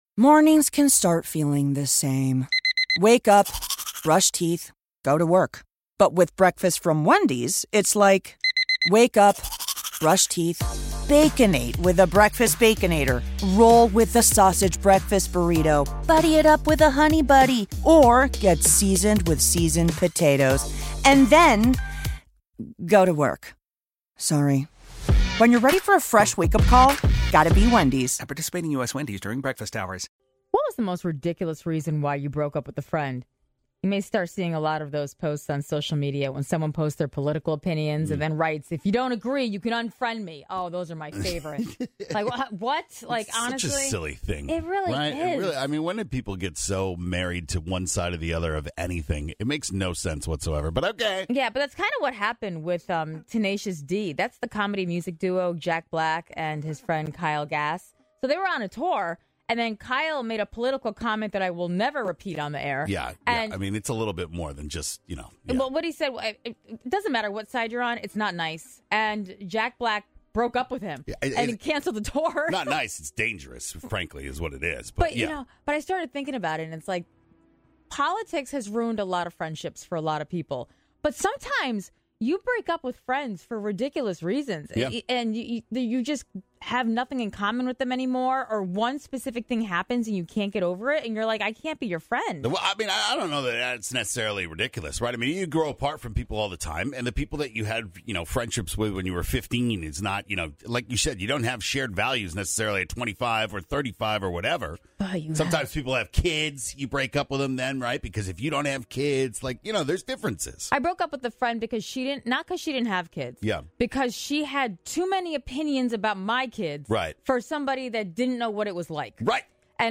You’re not alone, hear all the calls in the podcast including the one about feet…